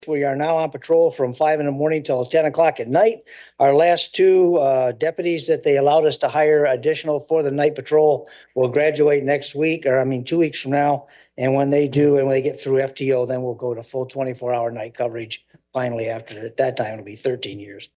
Sheriff Pollack gives final report to Coldwater City Council